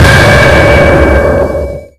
sonarTailWaterVeryCloseShuttle2.ogg